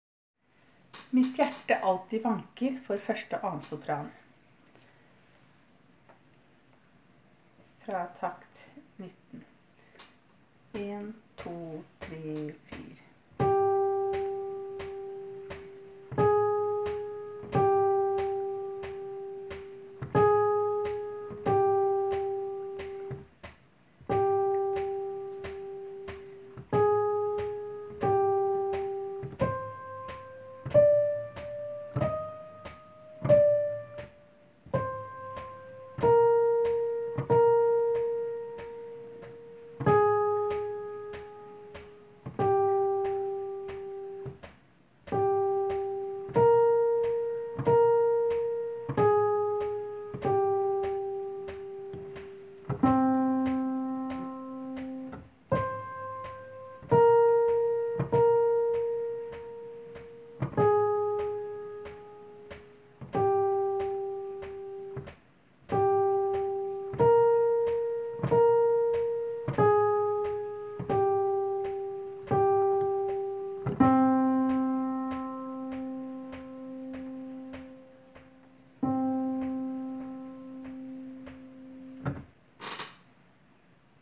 Jul 2017 Sopraner (begge konserter)
Mitt-hjerte-alltid-vanker-1-og-2-Sopran.m4a